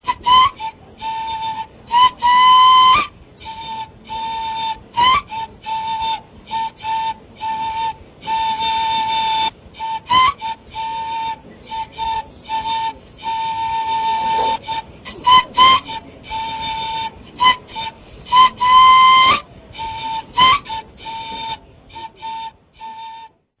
Flauta sin aeroducto
Indígena warao.
Aerófono de soplo, de filo.
Tubo de hueso fémur de las patas traseras venado.
Grabación: Toque ocasional
Característica: Melodía que se toca cuando van hacia la selva
Procedencia, año: Ranchería Morichito, estado Delta Amacuro, Venezuela, 1978